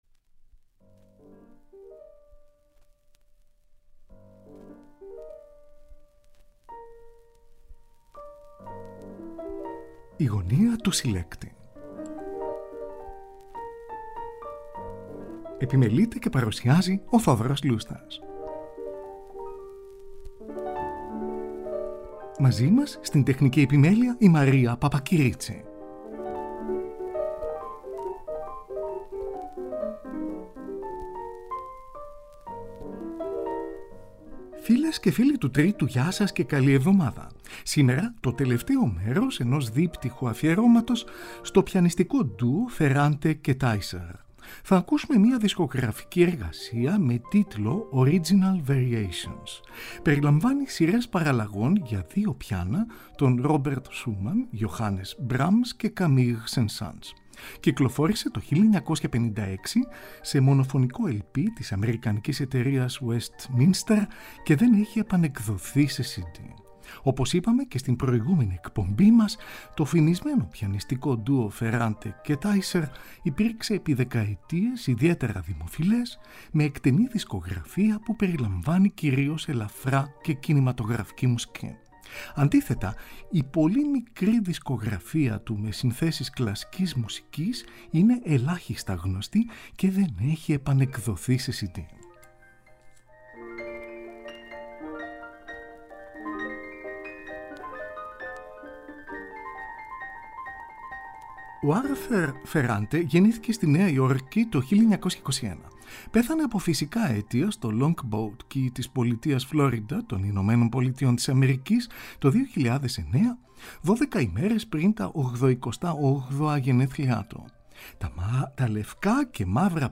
Andante και παραλλαγές για δύο πιάνα
για δύο πιάνα